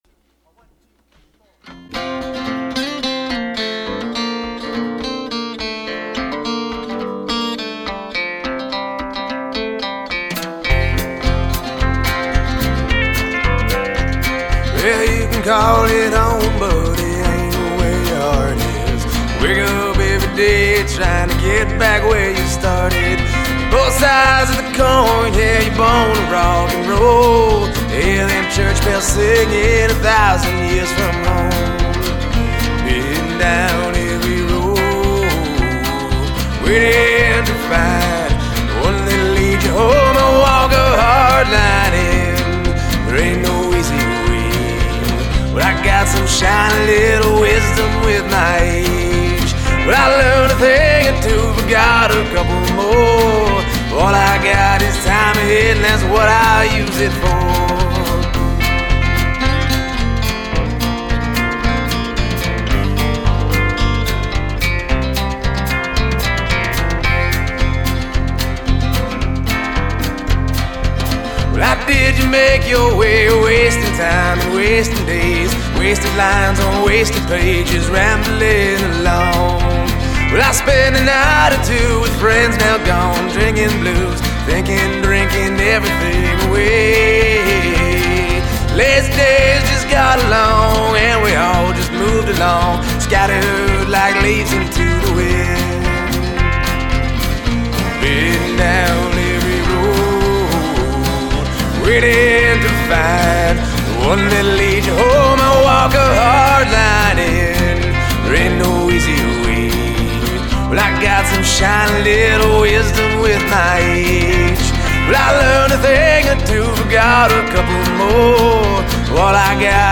It’s alt. folk, with a little rust around the edges.